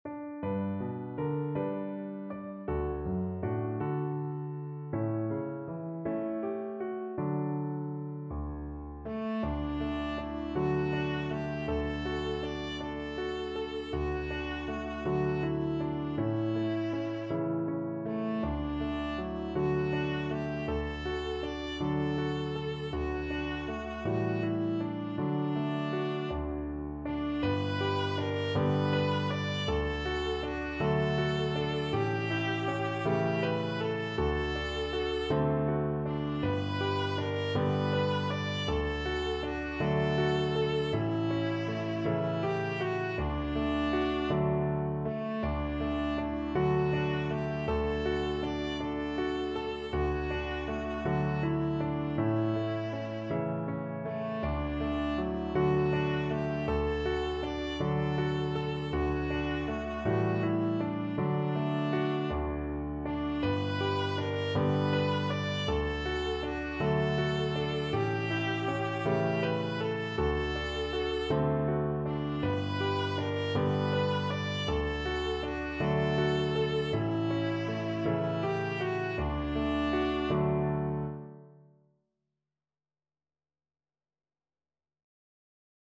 Andante espressivo
6/8 (View more 6/8 Music)